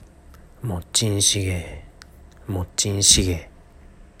モッジン　シゲ
【発音】